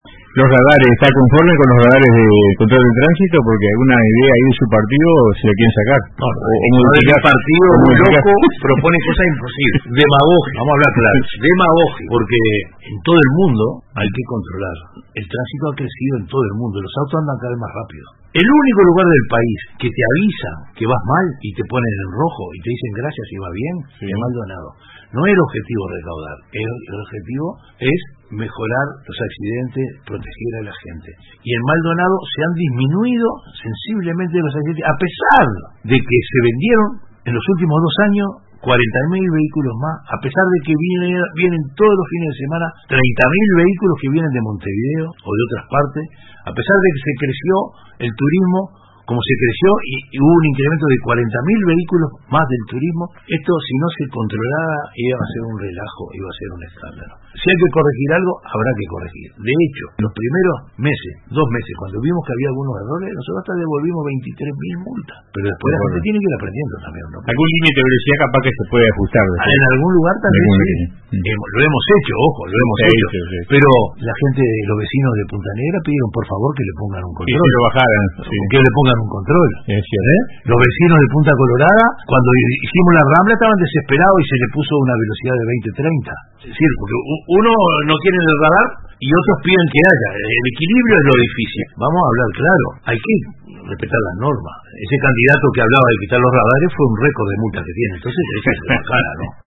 Durante su participación en el programa Radio con Todos de RBC, Antía sostuvo que “el objetivo no es recaudar, sino mejorar la seguridad vial y proteger a la gente”.